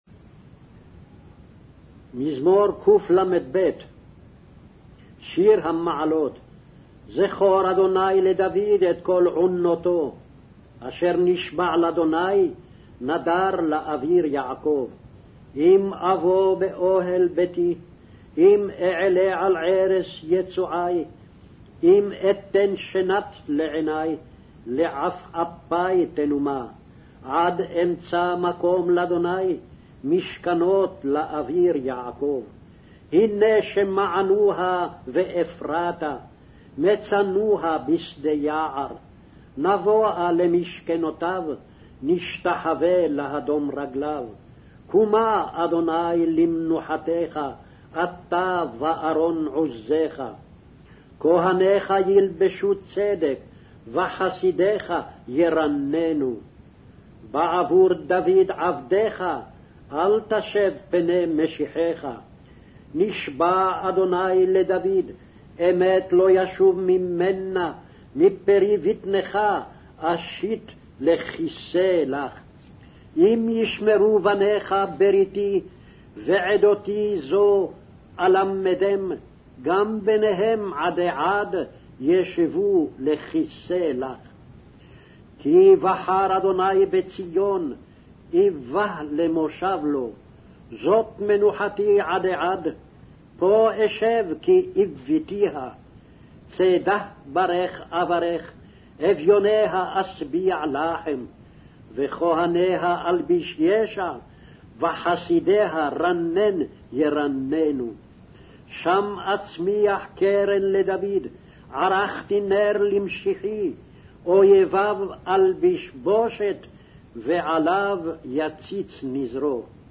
Hebrew Audio Bible - Psalms 89 in Ervta bible version